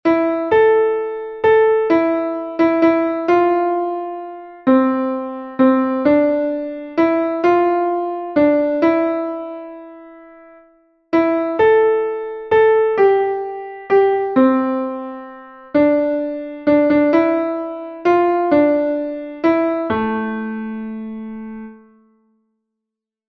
Als Volkslied klassifiziert